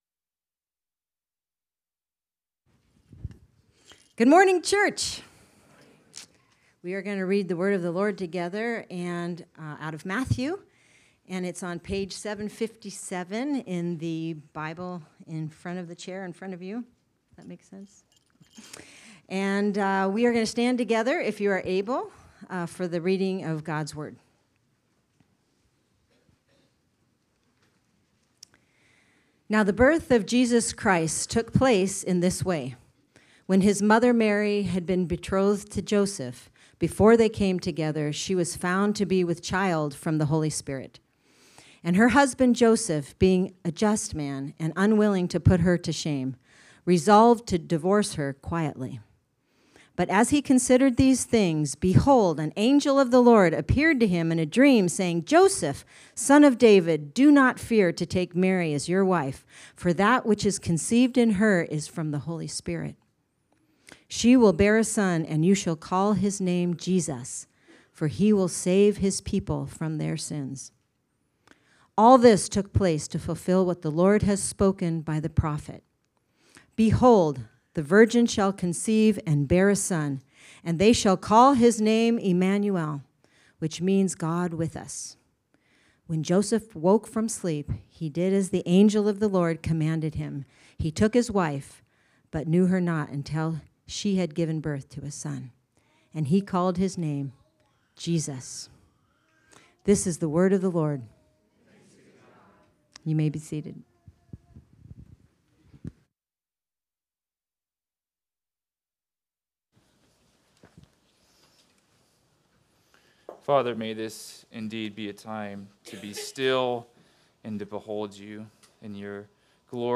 Sermons
Sermons from City Church of Gainesville: Gainesville, FL